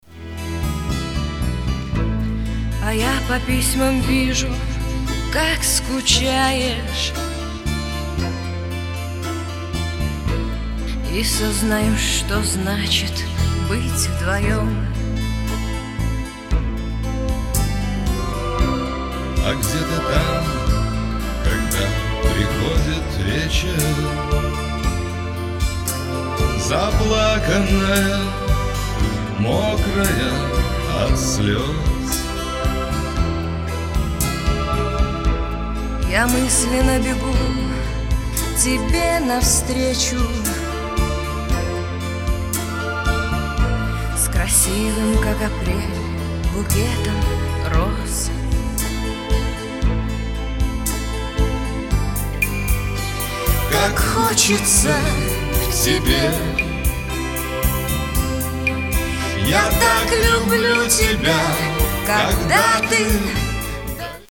• Качество: 256, Stereo
русский шансон
блатная песня
кабацкая песня